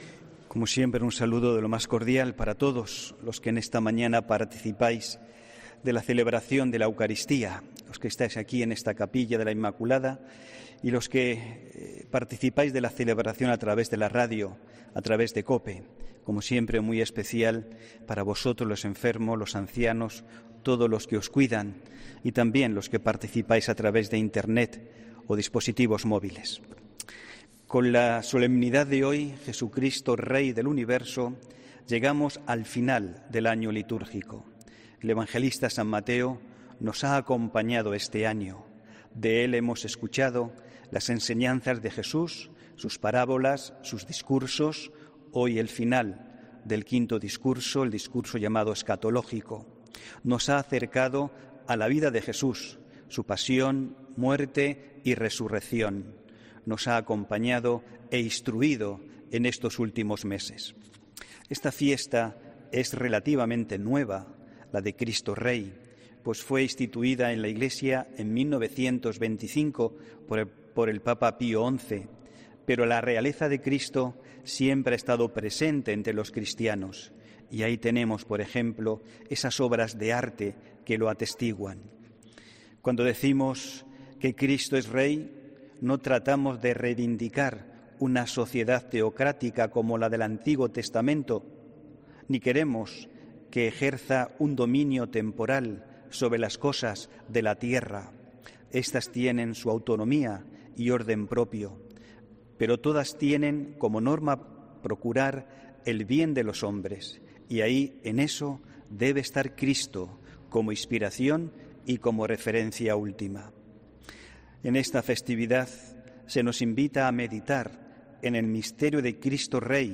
HOMILÍA 22 DE NOVIEMBRE DE 2020